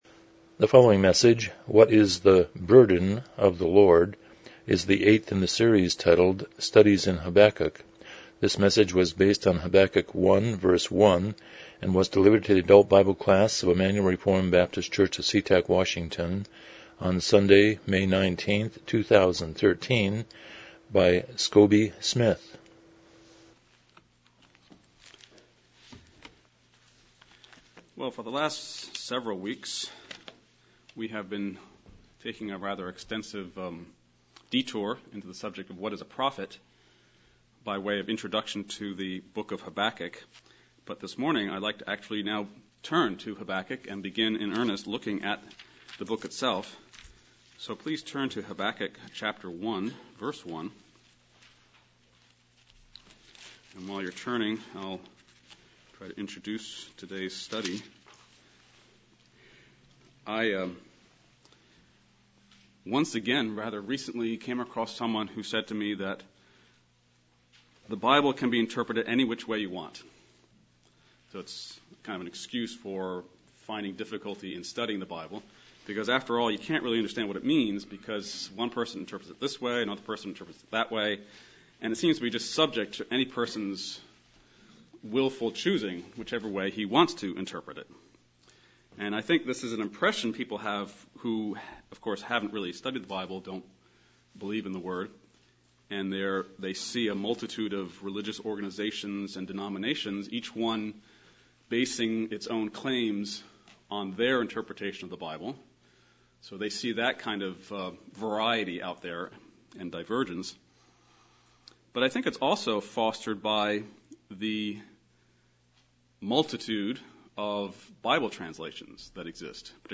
Series: Studies in Habakkuk Passage: Habakkuk 1:1 Service Type: Sunday School « 24 Proverbs 8:1-36 29 The Sermon on the Mount